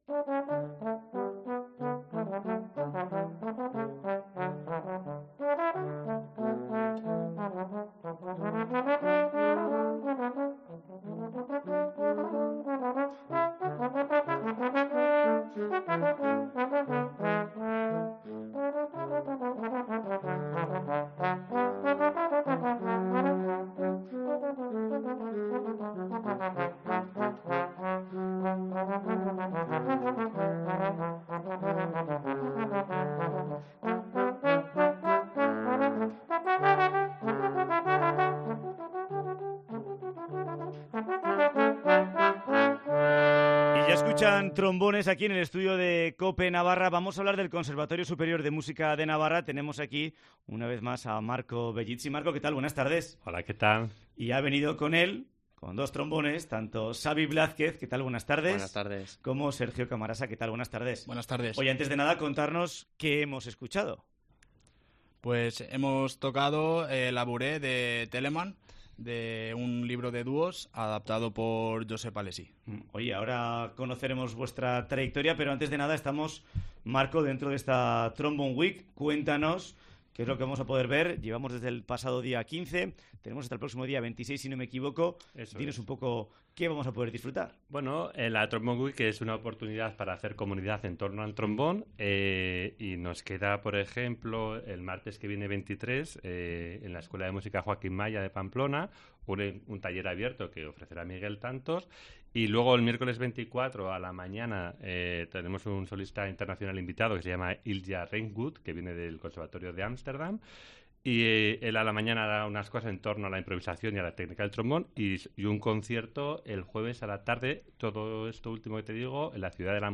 TROMBON WEEK